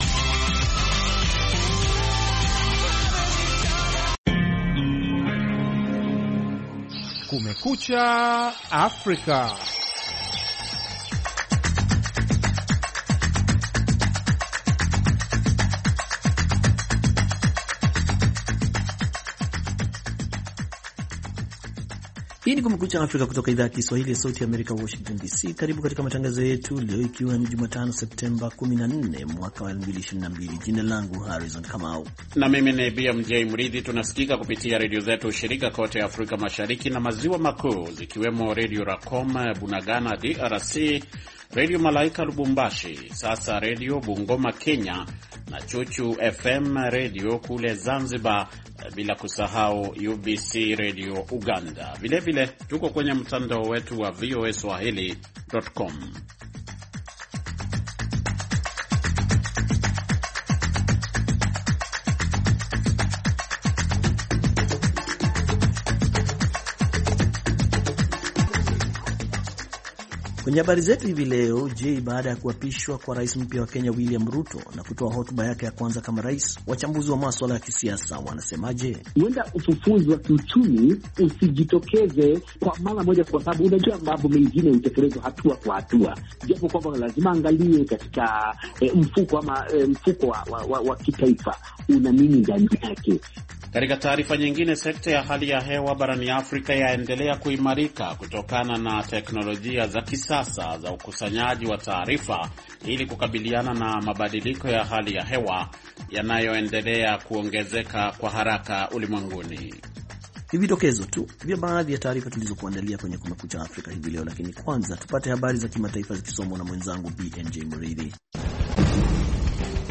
Tshisekedi ahutubia mkutano wa 77 wa baraza kuu la Umoja wa Mataifa